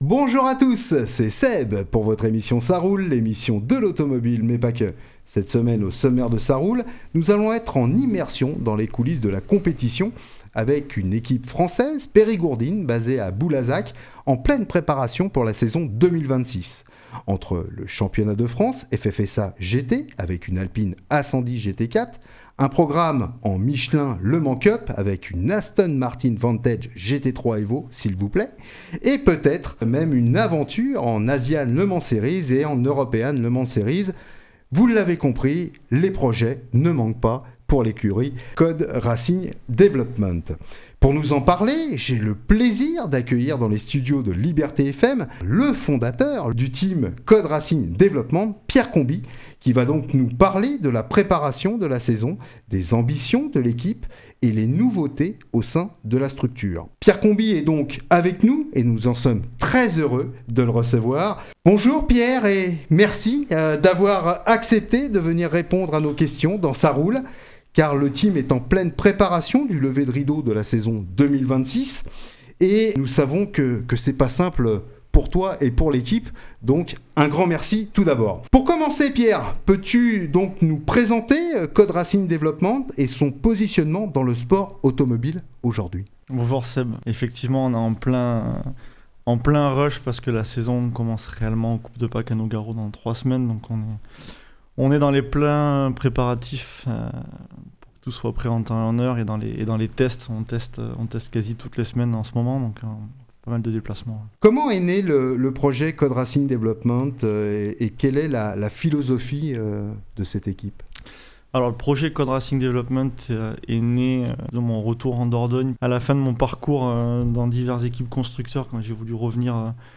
dans les studios de Liberté FM